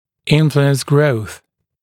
[‘ɪnfluəns grəuθ][‘инфлуэнс гроус]влиять на рост, оказывать влияние на рост